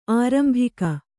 ♪ ārambhika